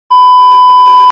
Censored Beep Sound Effect Free Download
Censored Beep